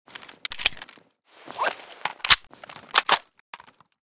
vz61_reload_empty.ogg.bak